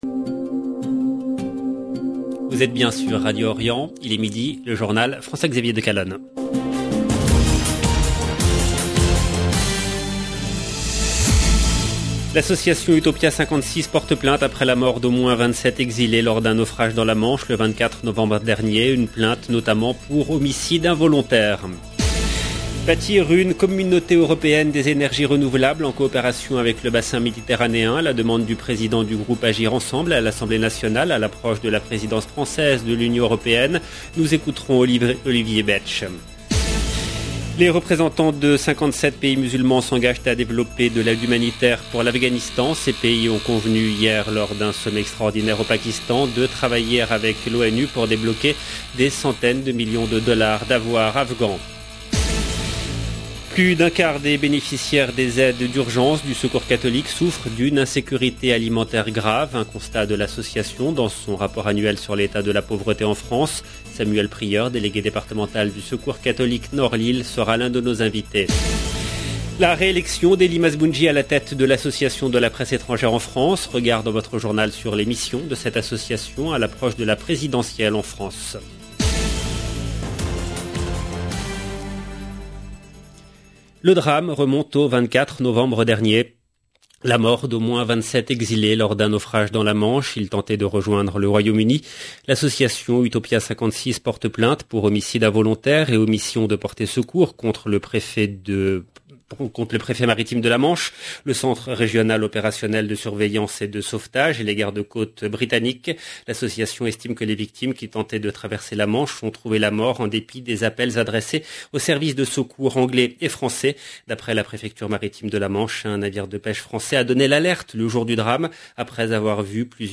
LE JOURNAL EN LANGUE FRANCAISE DE MIDI DU 20/12/21